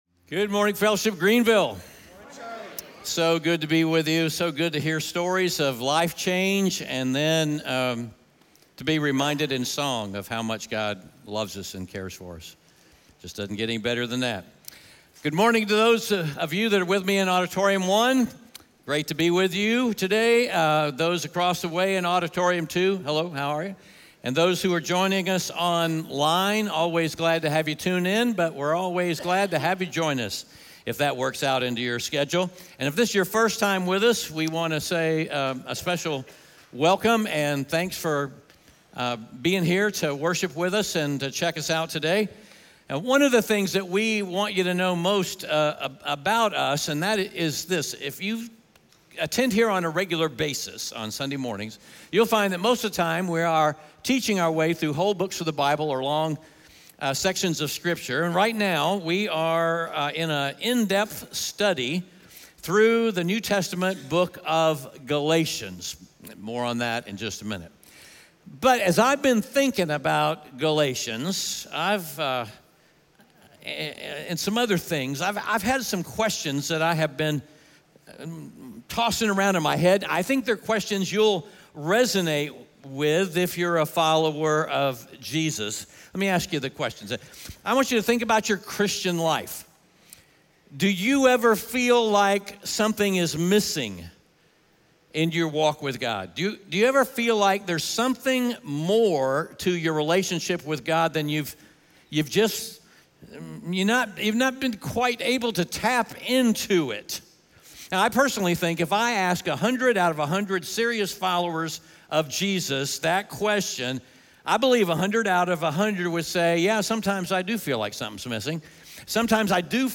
Galatians 3:1-5 Audio Sermon